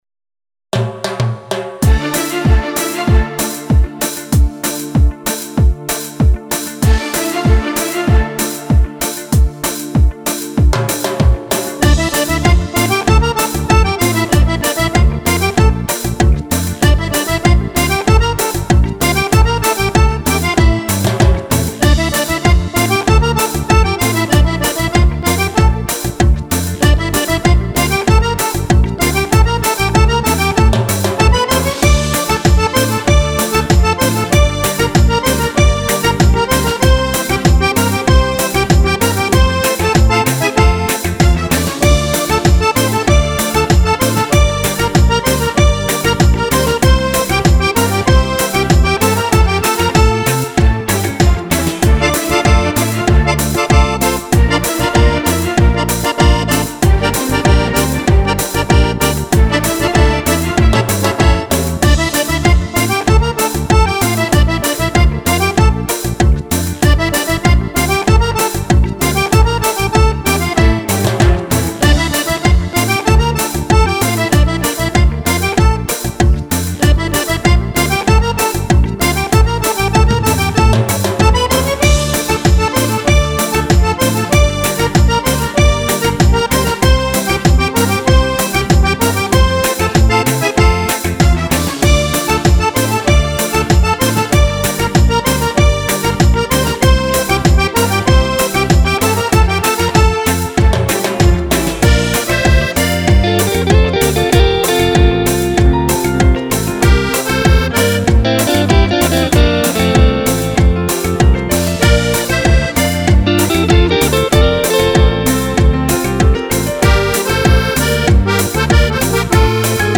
Cumbia per Fisarmonica